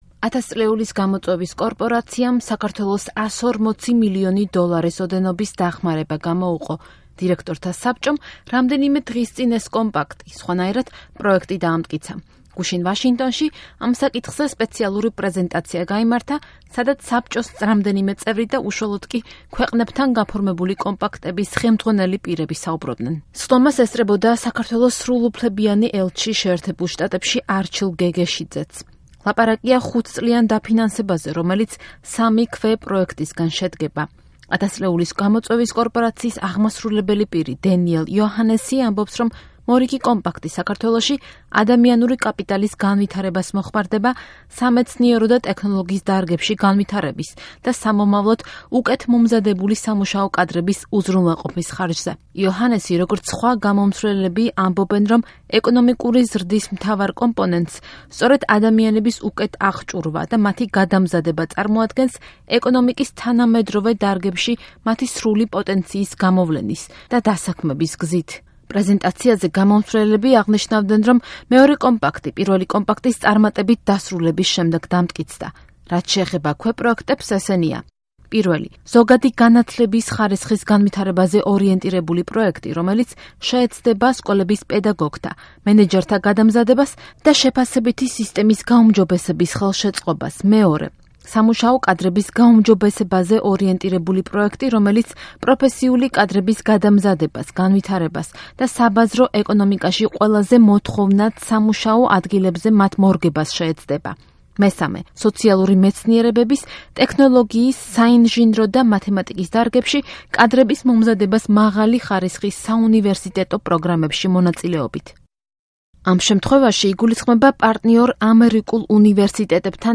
ინტერვიუ არჩილ გეგეშიძესთან